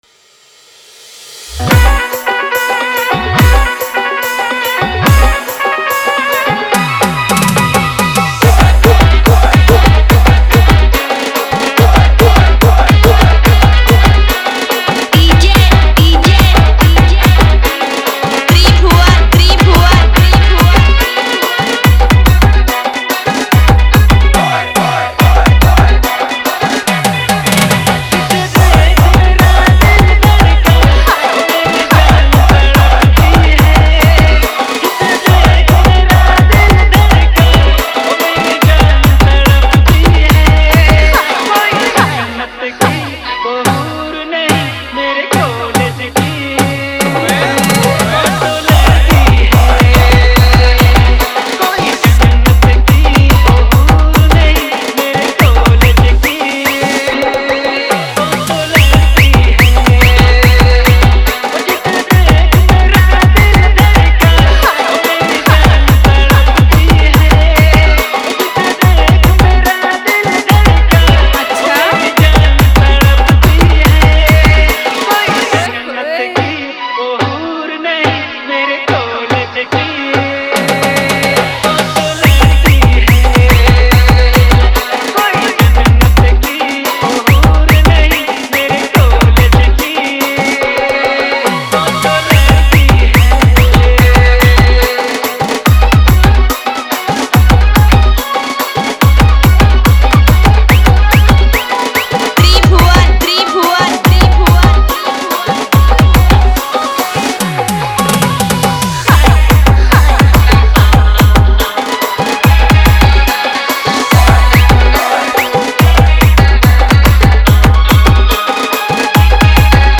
Nagpuri DJ song